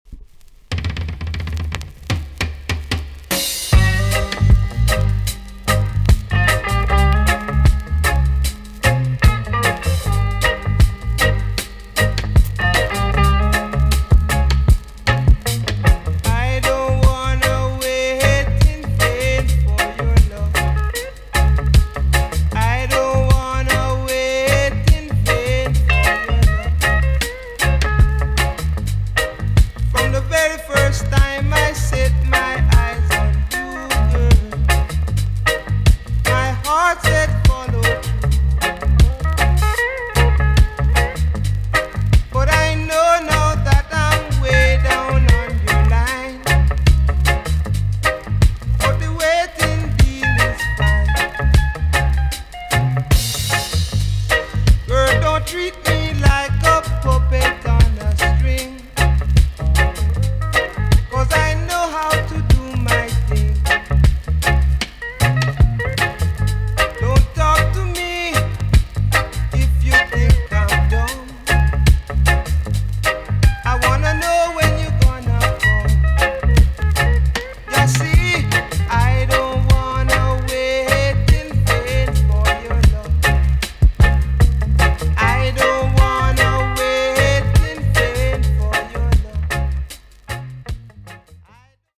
TOP >DISCO45 >VINTAGE , OLDIES , REGGAE
EX- 音はキレイです。